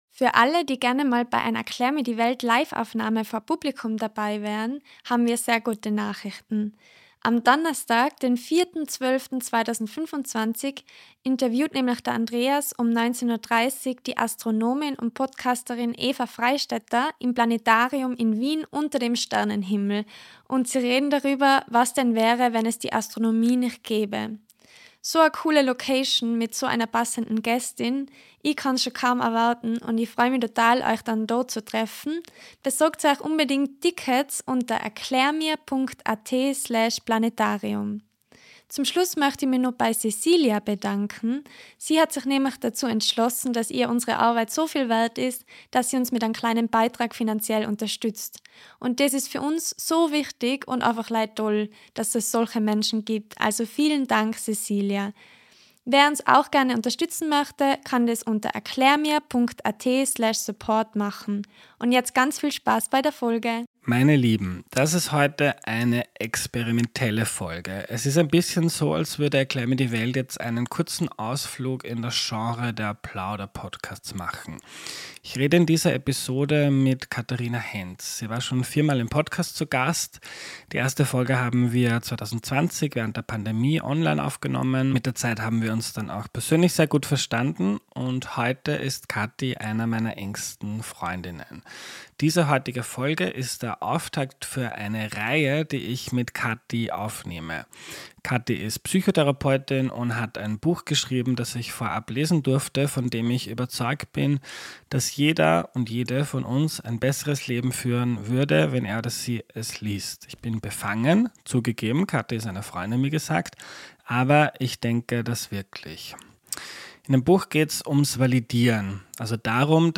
Bevor wir starten, gibt’s aber ein höchst persönliches Gespräch.